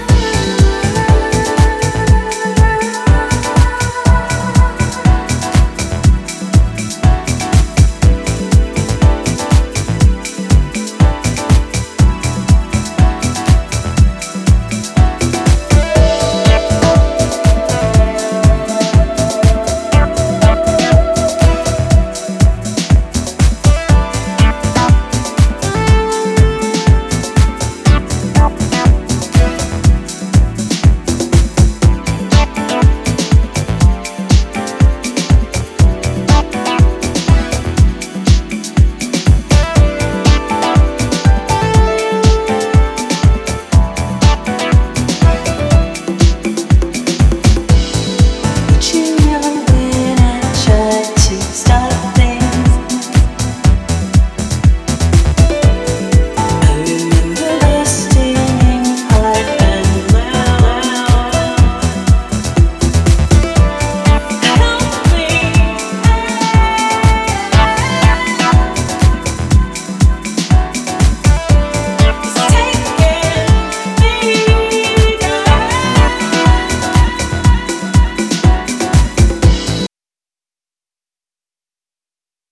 グルーヴと温かみが増した抜群のフュージョン・ハウスへと仕上げた、いつもながらさすがの仕上がりとなっています。
秀逸なディープ・ハウスをじっくり堪能できるおすすめ盤です！